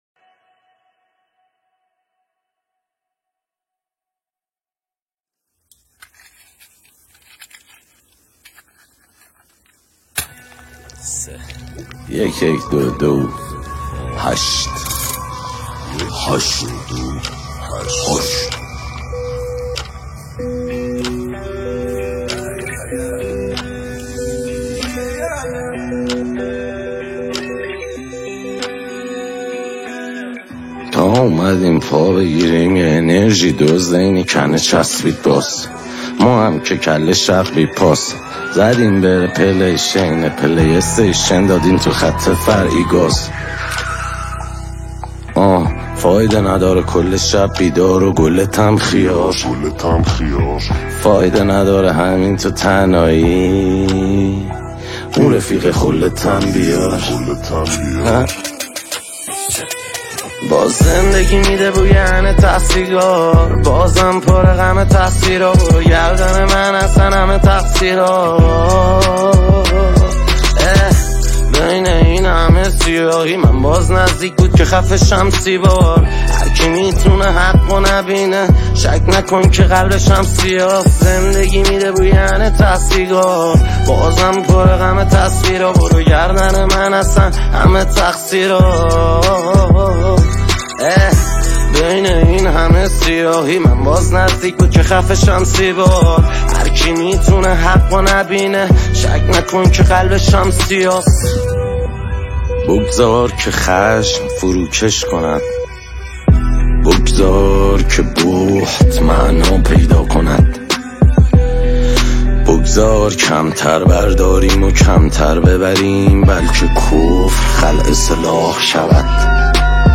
موزیک و اصلاح اهنگ های رپ